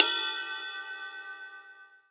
DJP_PERC_ (17).wav